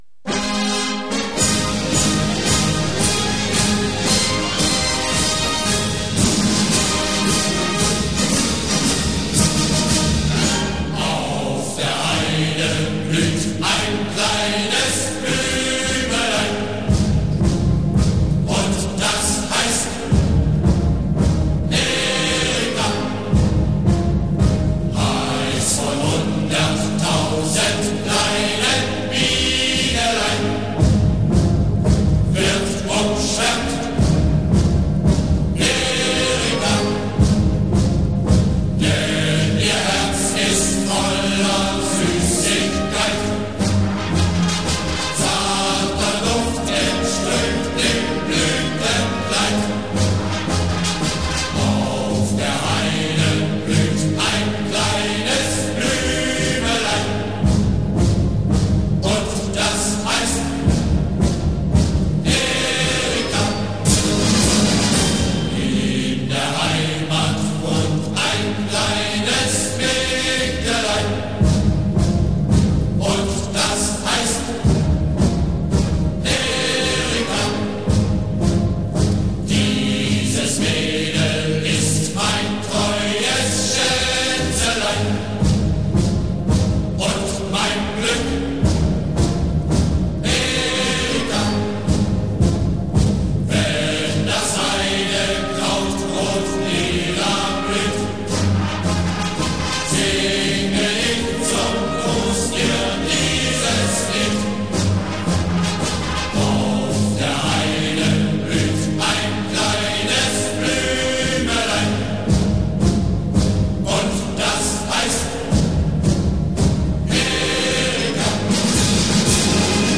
L’un des chants de marche préféré des armées nazies, en particulier des SS, avait pour titre « Erika »(1).
Pourtant quand on prend soin d’en écouter les paroles en Allemand entre deux assourdissants coups de cymbales et trois couinements de trompette, il n’y est question que « de petites abeilles qui butinent des petites bruyères »…..